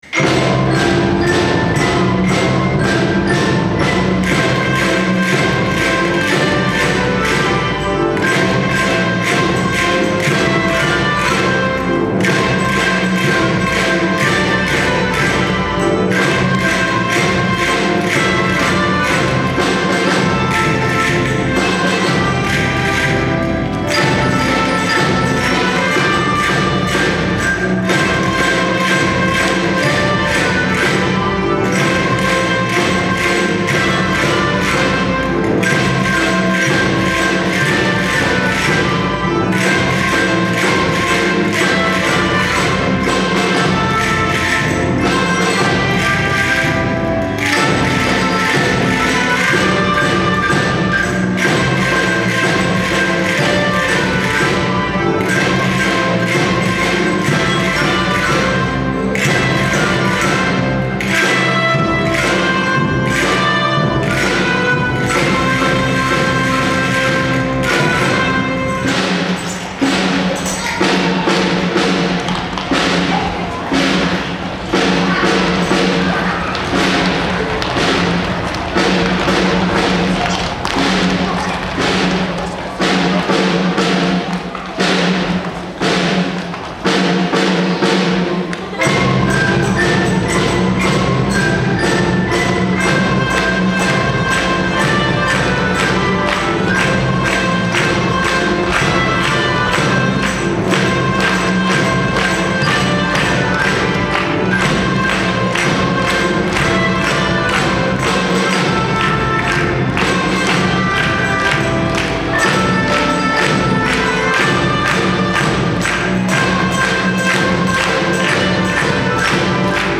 ２年生は鍵盤ハーモニカでメロディーを演奏しました。
１年生は２・３年生の音を聴きながらカスタネットを演奏しました。途中で早くなるリズムもばっちりマスターしました！
みんなの手拍子も加わり、最後には１年生によるシンデレラ城が完成！